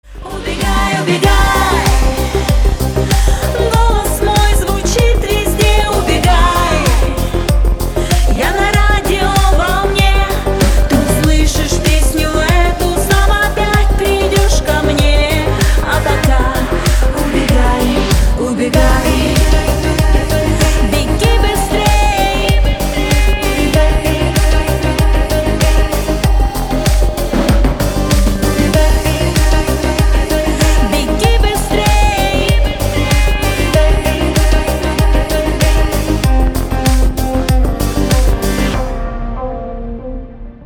• Качество: 320, Stereo
поп
женский вокал
попса